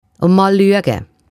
Kurs Walliser Deutsch